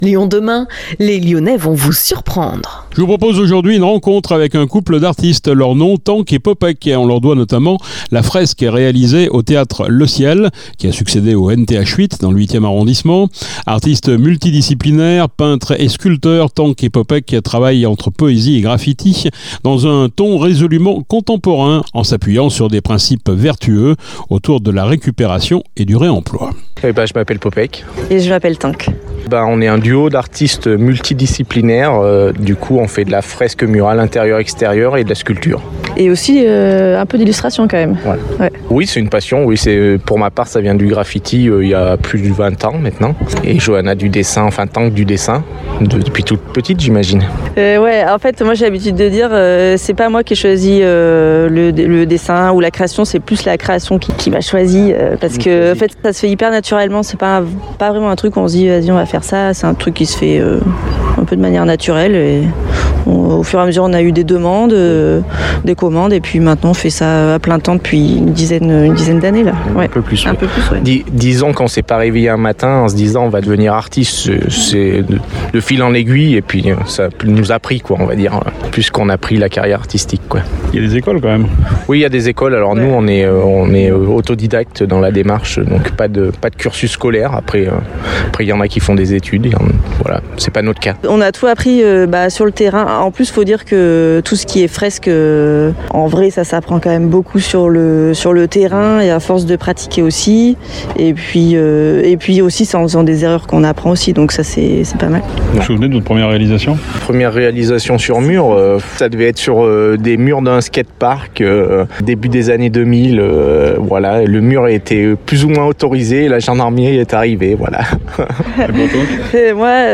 Halle des Girondins